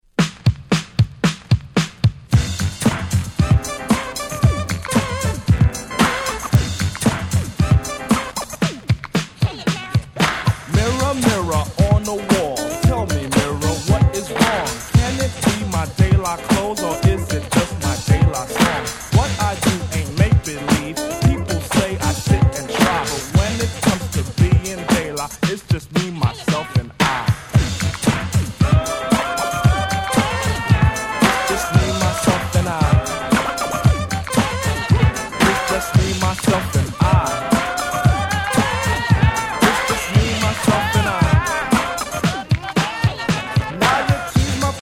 89' Super Hit Hip Hop !!
Funkネタって事もあり、叔父様達にも非常に人気！
80's Boom Bap ブーンバップ ミドルスクール